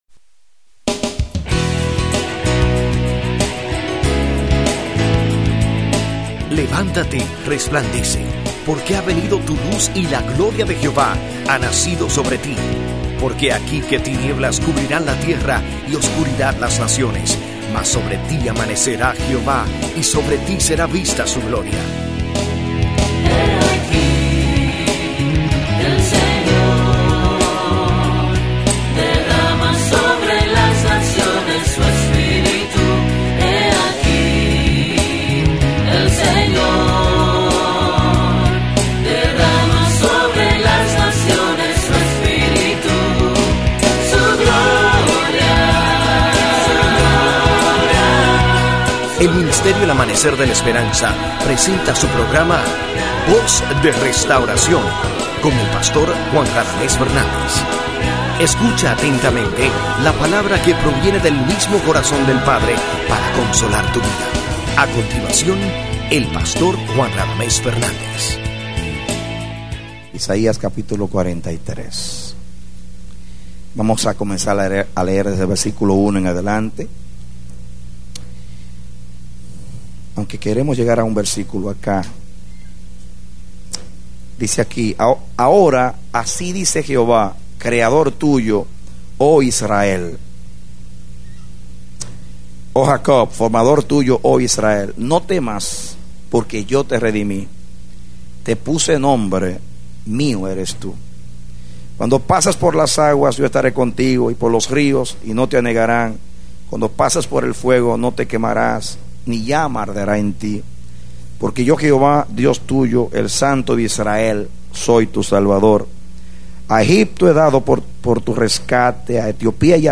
A mensaje from the serie "Eres un Linaje Santo."